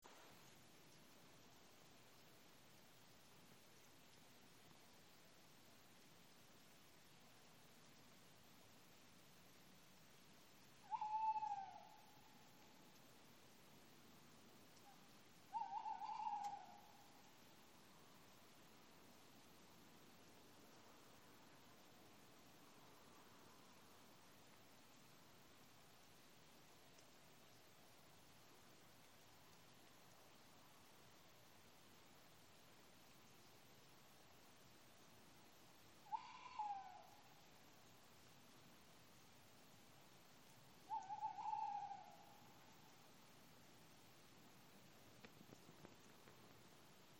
серая неясыть, Strix aluco
СтатусПоёт
Примечания/provocēta no kapiem. interesants dialekts
UPD: interpretēts kā uztraukuma sauciens